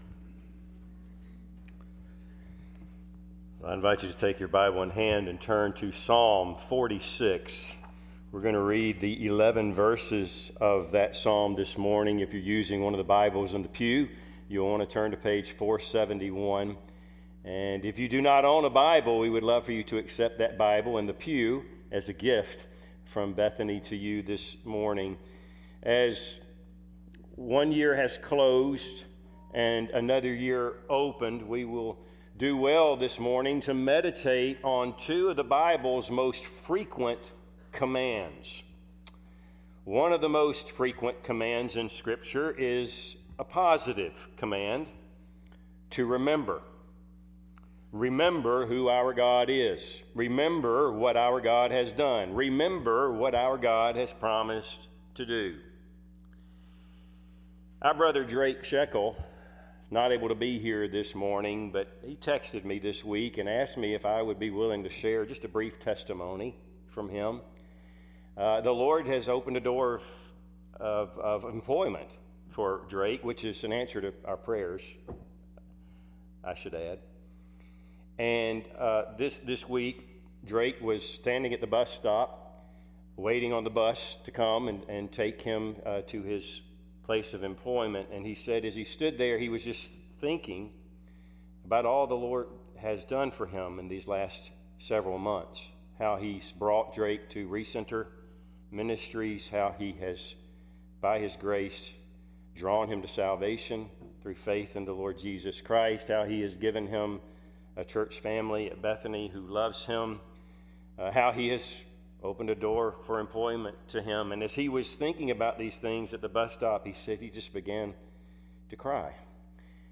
Service Type: Sunday AM Topics: Faith , God's faithfulness , God's power , God's presence « Man of Sorrows God’s Glorious Gospel